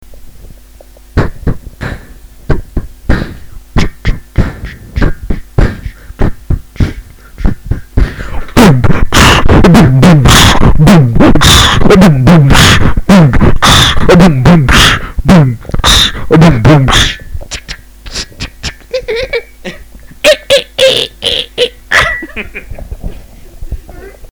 beatboxing.mp3